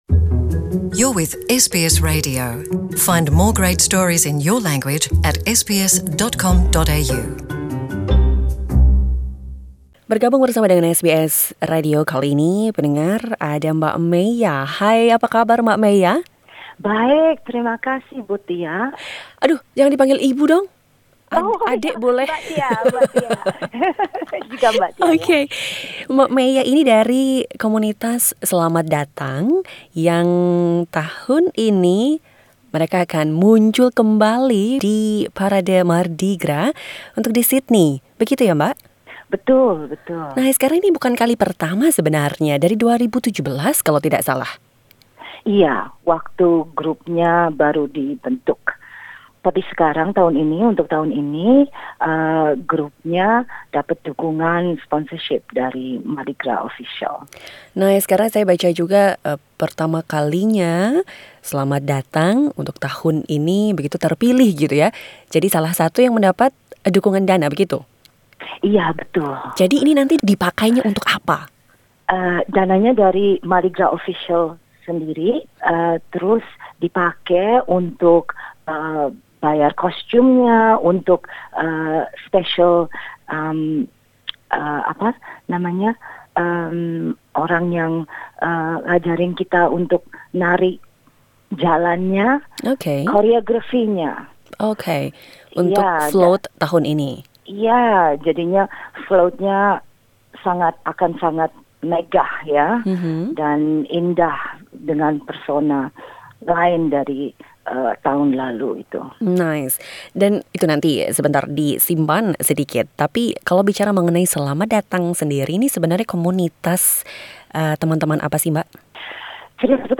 SBS Indonesian berbincang dengan